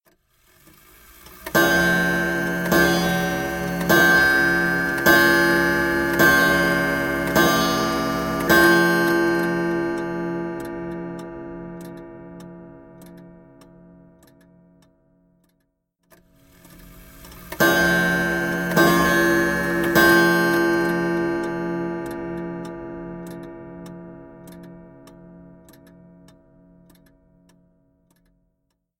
7-3 Chime
Tags: clock